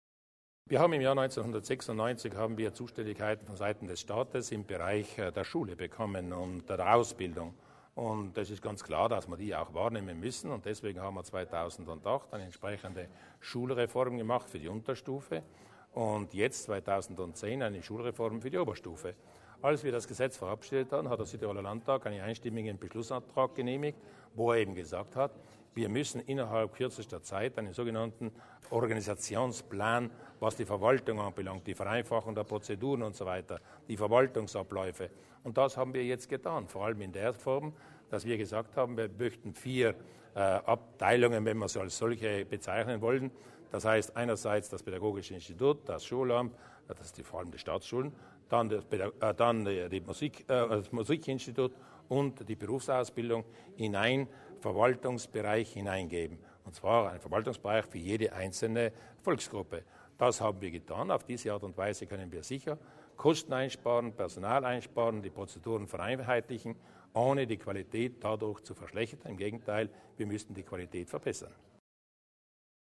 Landesrätin Kasslatter zu den Zielen der Reorganisation